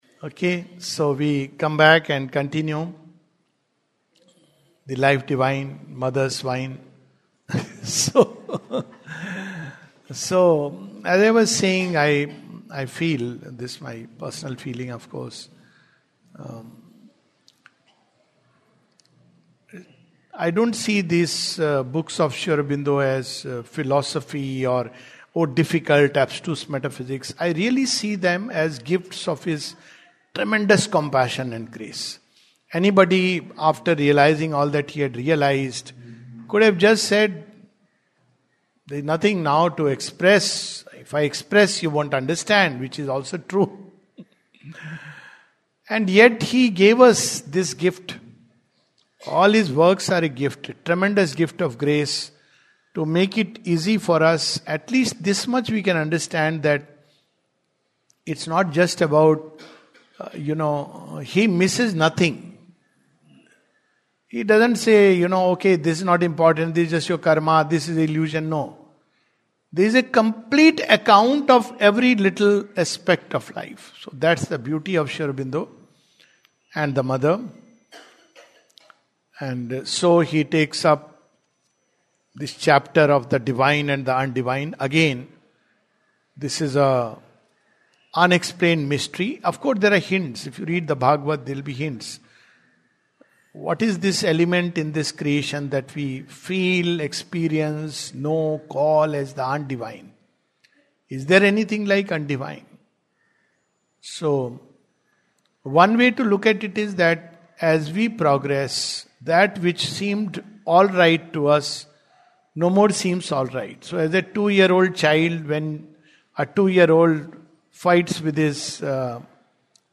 The Life Divine, 24th February 2026, Session # 06-06 at Sri Aurobindo Society, Pondicherry - 605002, India. The Divine and the Undivine (Part 2).